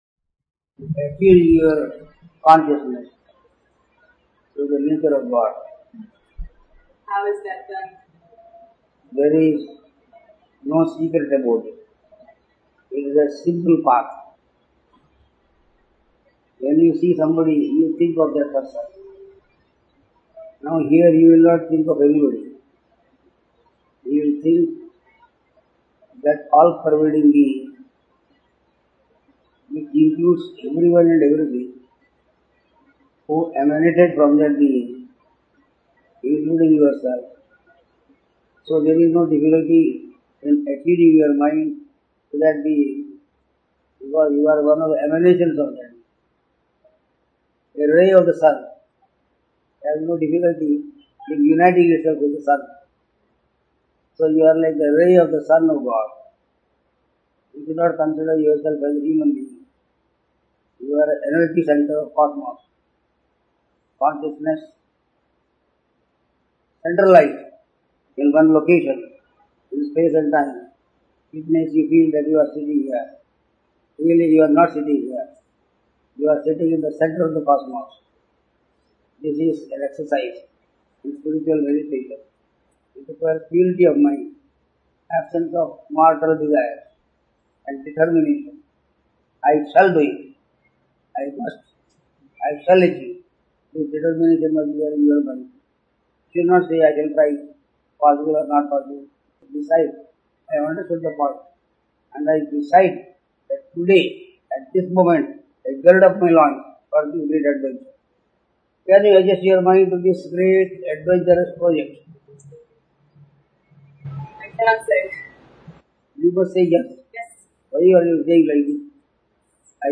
Darshan of Swami Krishnananda in 1999
(Darshan given in April 1999)